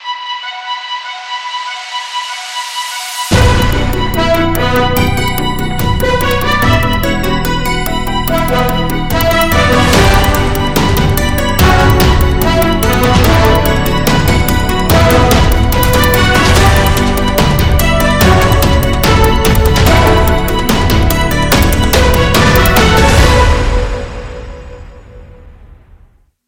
Pilot: BOOM BOOM BANG! Mon, 31 Mar 2025 17:48:11 GMT From Workplace Shooters Inspired by ‘Virtual-Reality’ to Tech CEOs attending their assessment trials, Tomorrow Tonight celebrates the release of its pilot episode. Synopsis: Tomorrow Tonight is a new Anthology, Science Fiction, News Podcast by DarkTreeAudio Productions. It’s a look-forward at all of the terrible news of the future.